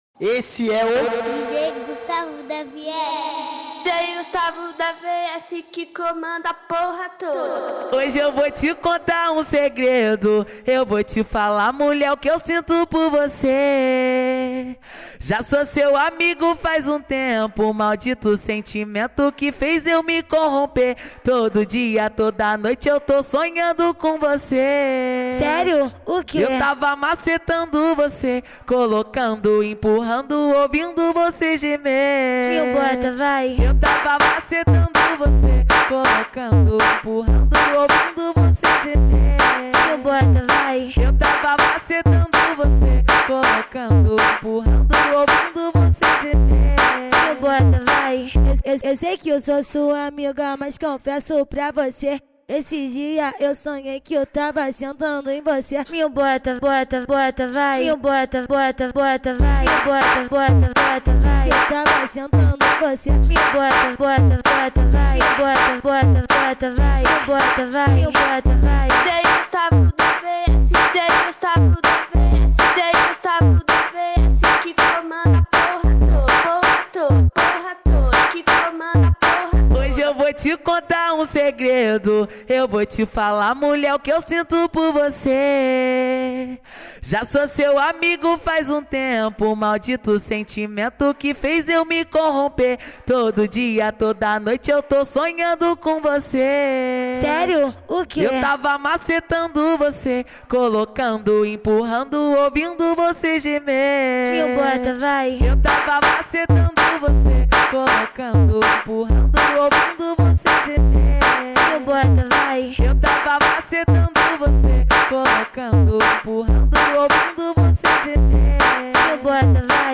2024-10-22 20:07:56 Gênero: Phonk Views